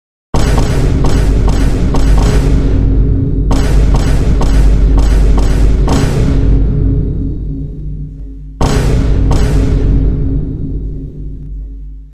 Vine Boom Spamming Sound Effect Free Download
Vine Boom Spamming